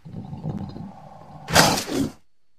water_life_crocattack.ogg